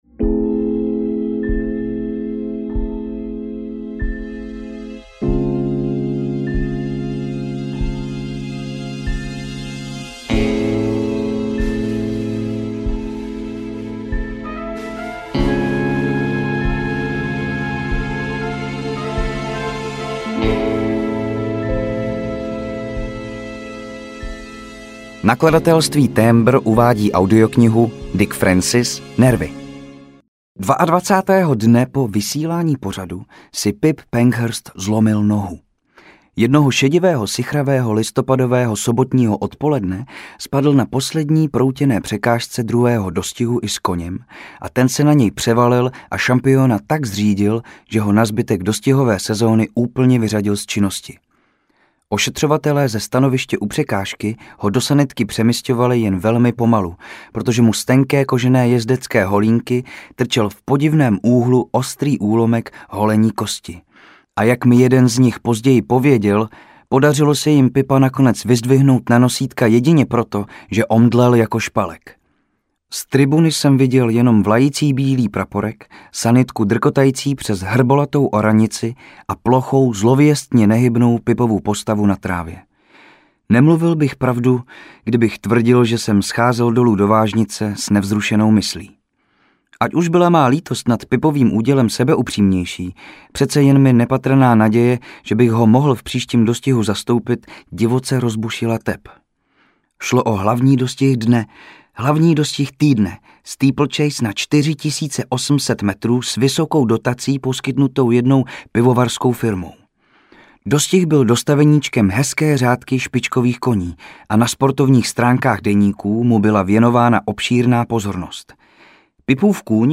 Nervy audiokniha
Ukázka z knihy